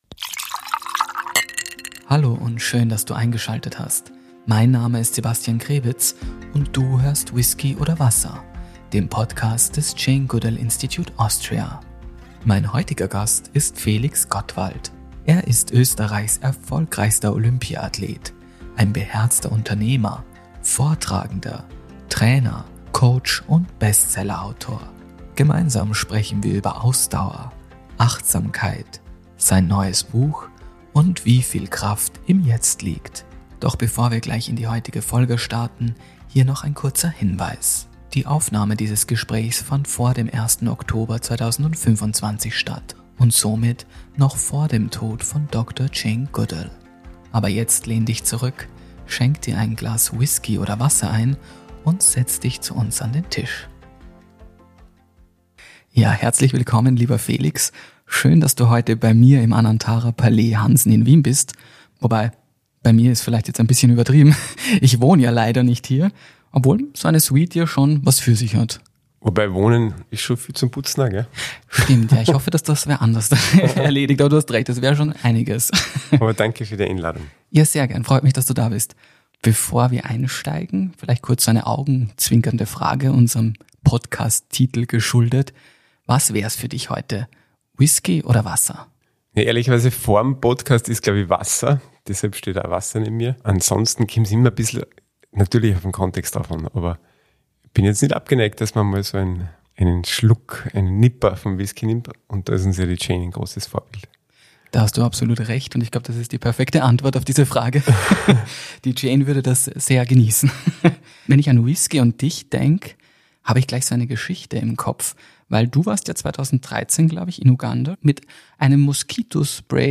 Ein Gespräch über Selbstvertrauen, Präsenz und sein neues Buch.